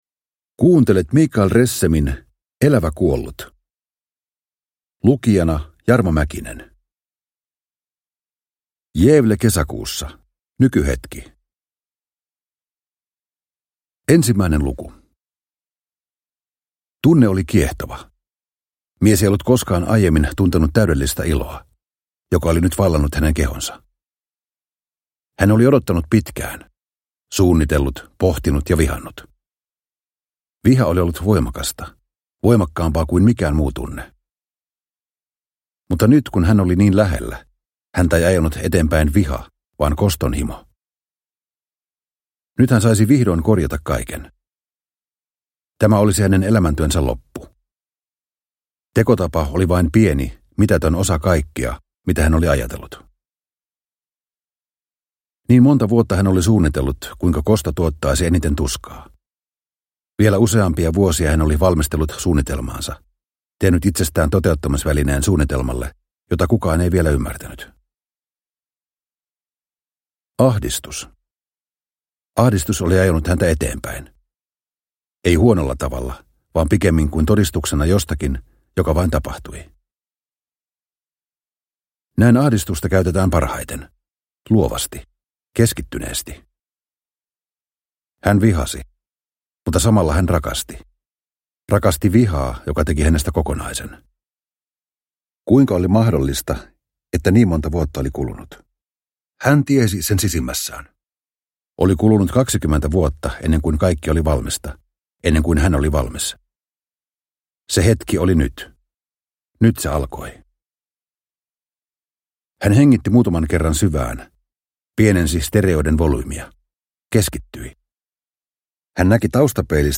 Elävä kuollut – Ljudbok – Laddas ner